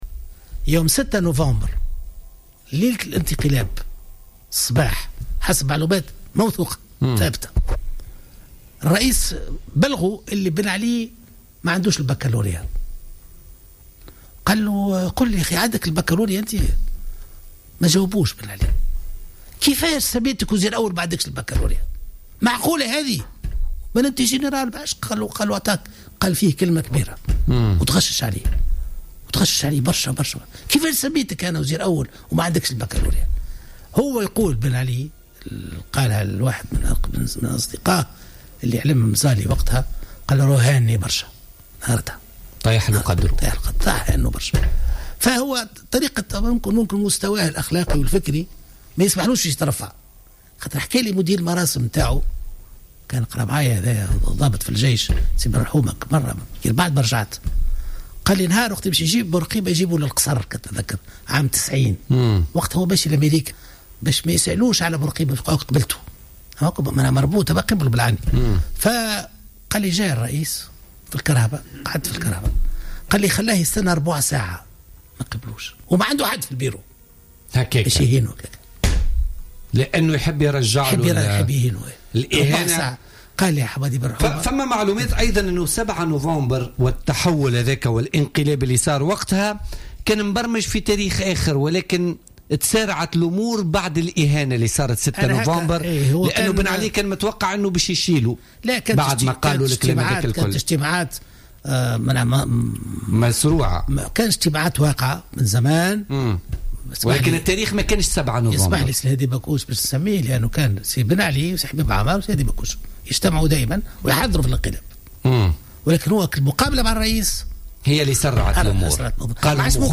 تحدث أحمد بنّور مدير الأمن والمخابرات وكاتب الدولة للدفاع في عهد الرئيس الحبيب بورقيبة في تصريح للجوهرة أف أم في برنامج بوليتكا لليوم الخميس 24 مارس 2016 عن انقلاب 7 نوفمبر 1987.